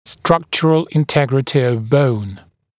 [‘strʌkʧərəl ɪn’tegrətɪ əv bəun][‘стракчэрэл ин’тэгрэти ов боун]структурная целостность кости